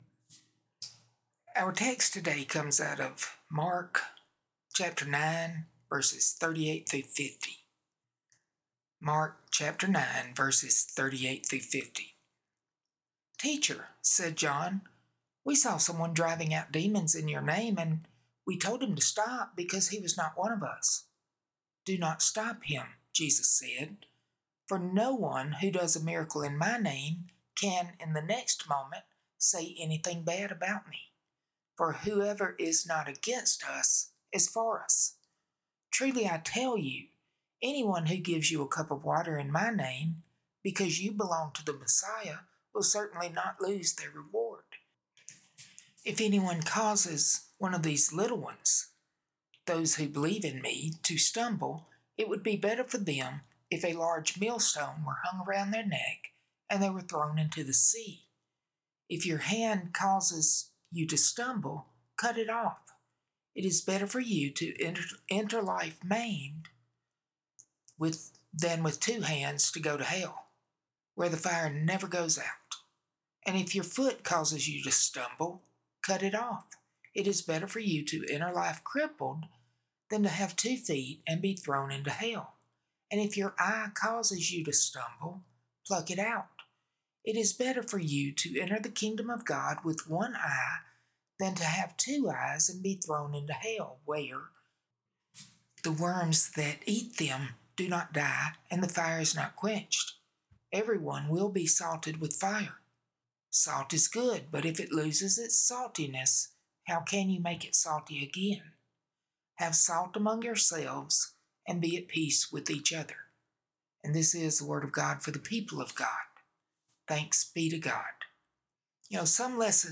9/28/25 Sermon